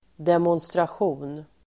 Uttal: [demånstrasj'o:n]